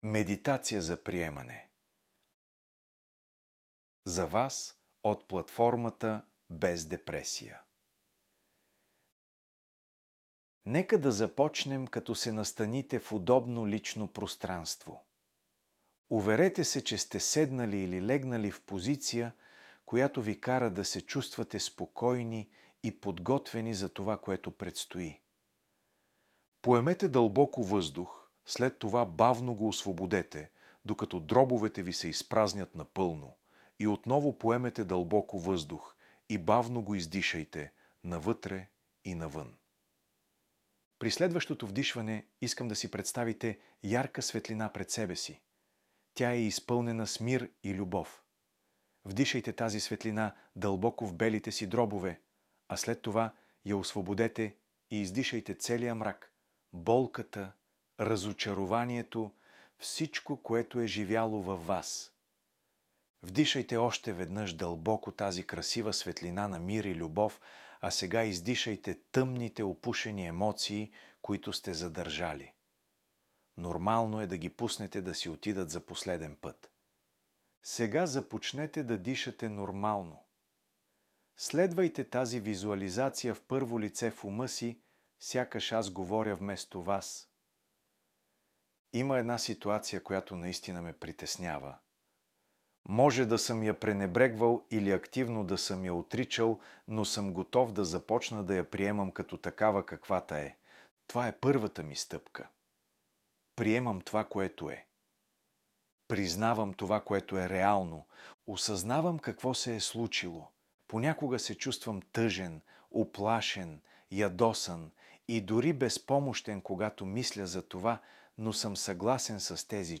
::: аудио статия 2 ::: Медитация за приемане